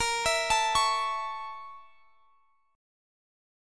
BbmM7b5 Chord
Listen to BbmM7b5 strummed